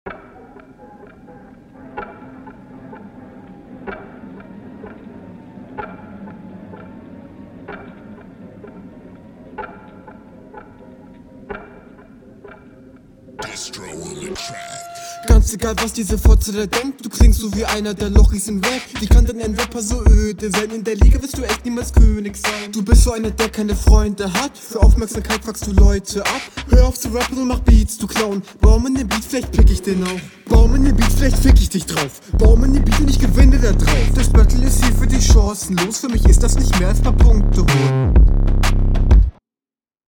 find dich hier stimmlich deutlich besser als in deiner RR, bist flowlich auch deutlich besser, …